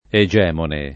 egemone
[ e J$ mone ]